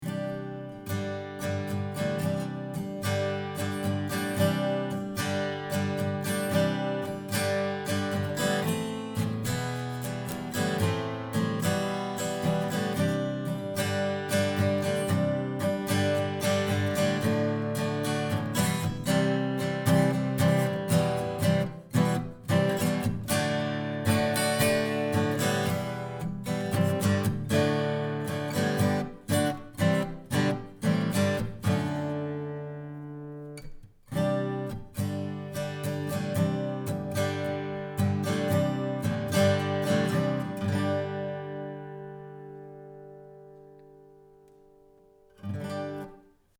Here are 9 quick, 1-take MP3s using this C34 in a large room into a Audient Black preamp into a Sony PCM D1 flash recorder, with MP3s made from Logic. These tracks are just straight signal with no additional EQ, compresson or effects:
SANTA CRUZ OM/PW: